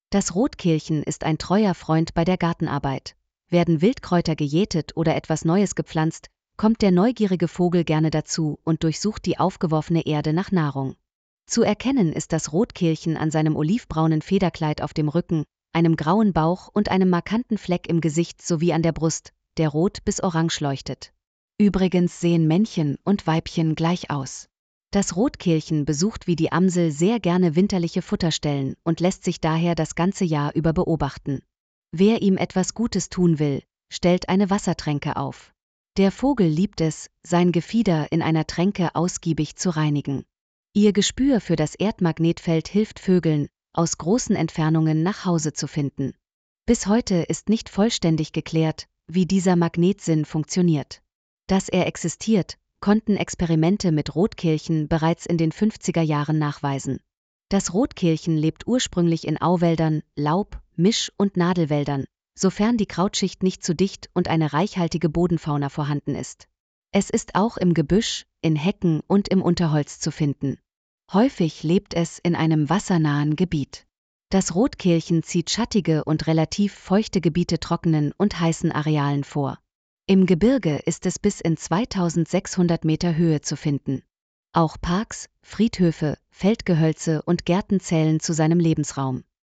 Steckbrief zum Hören